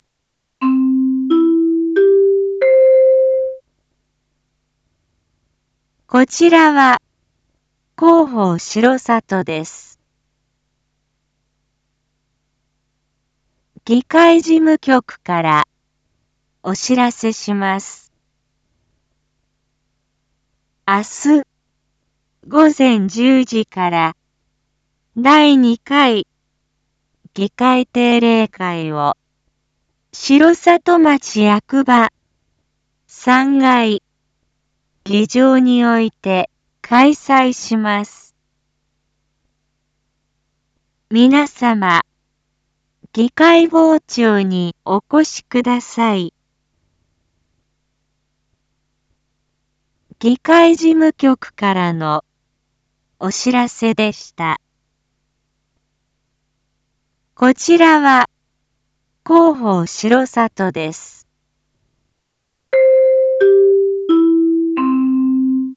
BO-SAI navi Back Home 一般放送情報 音声放送 再生 一般放送情報 登録日時：2023-06-05 19:01:07 タイトル：6/5夜 議会 インフォメーション：こちらは広報しろさとです。